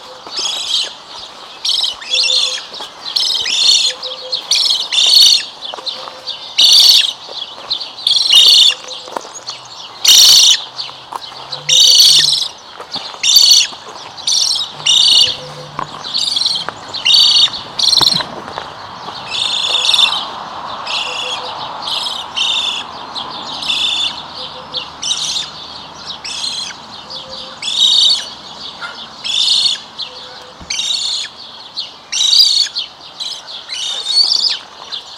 Ibis falcinelle - Mes zoazos
ibis-falcinelle.mp3